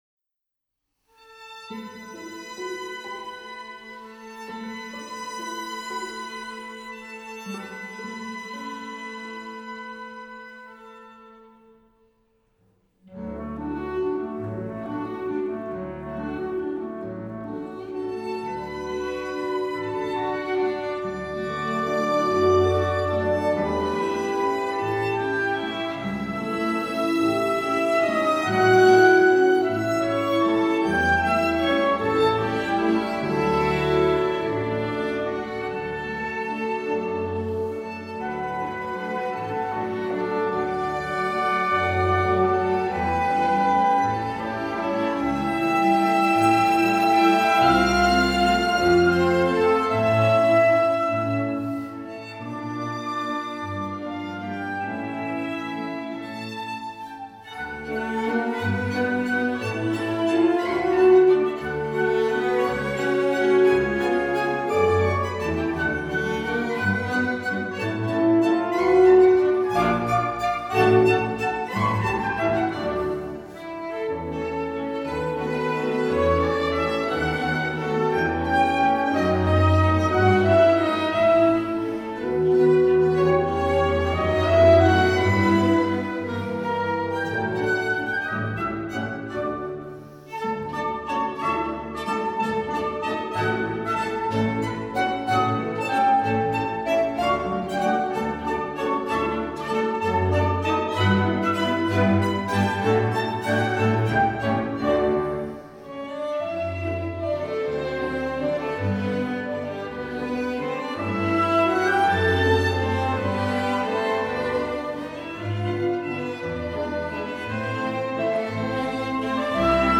by The Chamber Orchestra by waltz in vienna | The Original Viennese Waltz